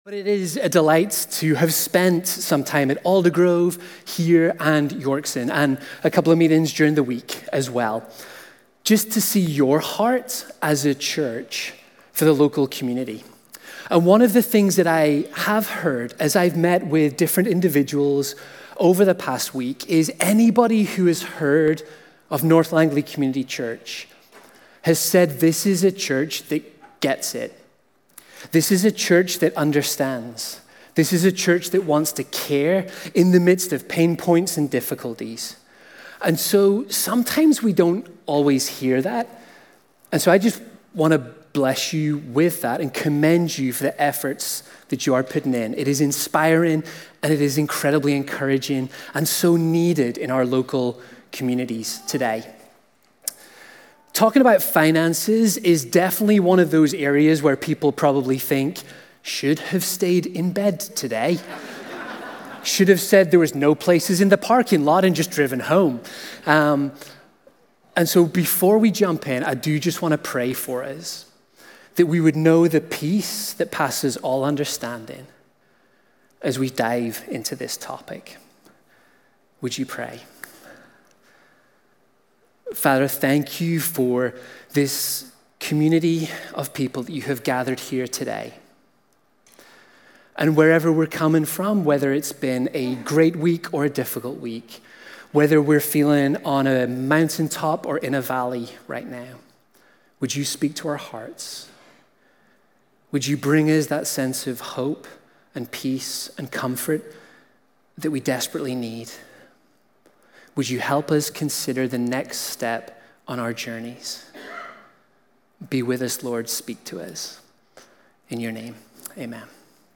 Walnut Grove Sermons | North Langley Community Church